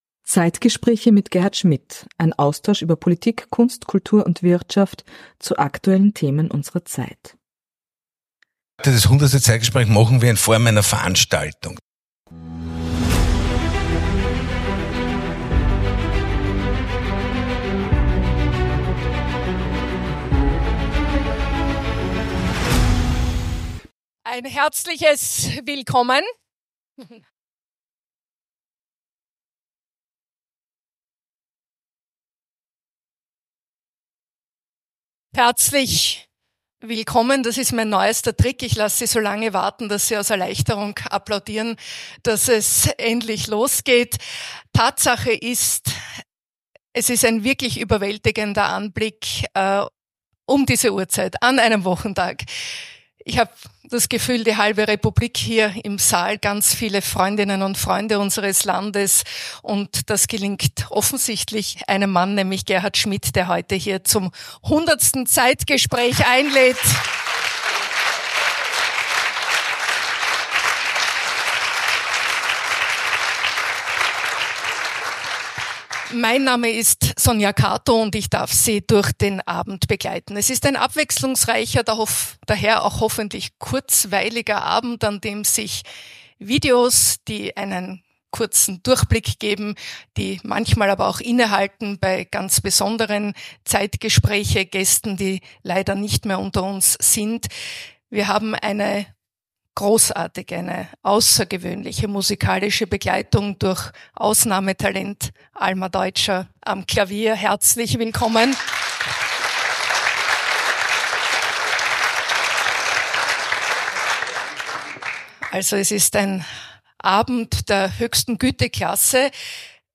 Die 100. Ausgabe der ZEITGESPRÄCHE war ein echtes Highlight, und die Wiener Urania war voller Energie und inspirierender Gespräche!
Besonders musikalisch wurde es mit Alma Deutscher, die nicht nur am Klavier begeisterte, sondern auch die Veranstaltung mit ihren Kompositionen bereicherte. Dieses besondere Treffen der Köpfe war nicht nur ein Rückblick auf spannende Gespräche und Begegnungen, sondern auch ein starker Impuls für zukünftige Diskussionen und Ideen.